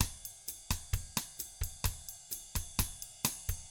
129BOSSAF3-L.wav